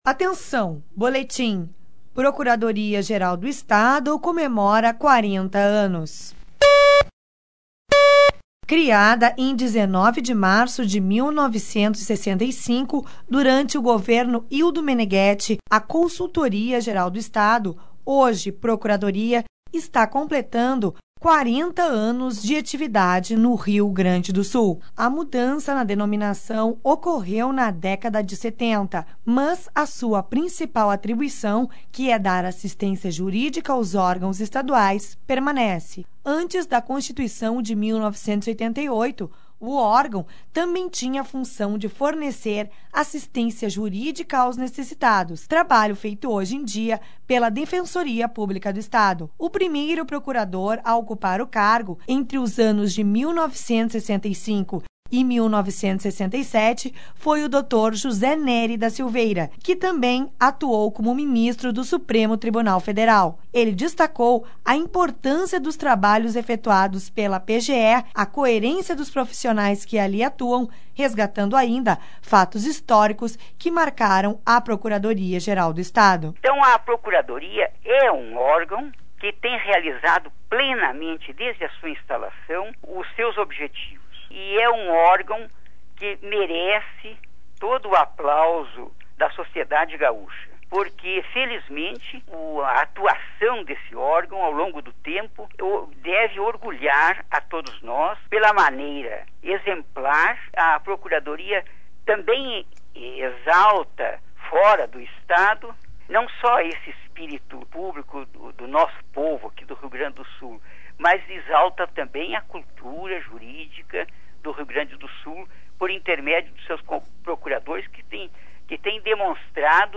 Criada em 19 de março de 1965, a Consultoria Geral do Estado - hoje procuradoria, está completando 40 anos de atividade no Rio Grande do Sul. Sonoras: Dra. Helena Maria Coelho, Procuradora Geral do Estado; Dr. José Nery da Silveira, ex-procurador geral